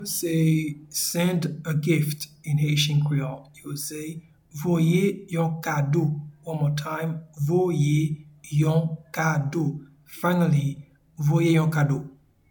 Pronunciation and Transcript:
Send-a-gift-in-Haitian-Creole-Voye-yon-kado.mp3